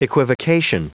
Prononciation du mot equivocation en anglais (fichier audio)
Prononciation du mot : equivocation